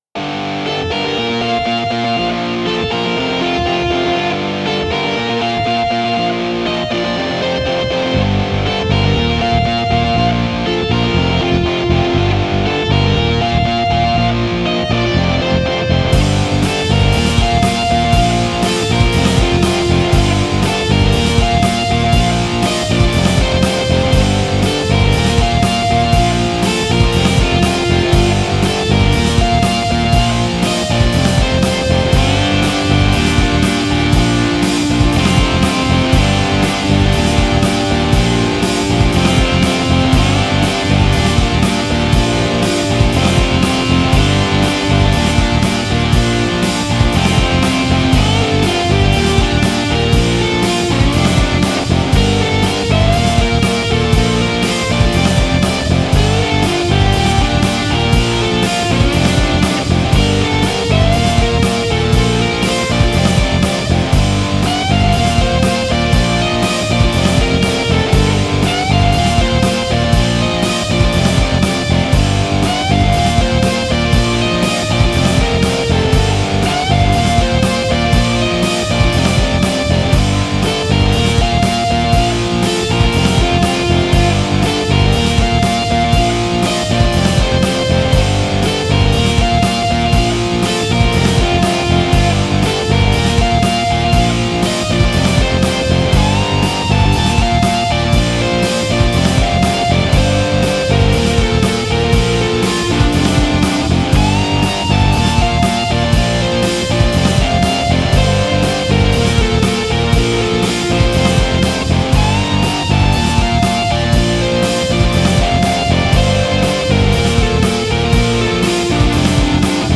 渋くてかっこいいギター曲です。【BPM120】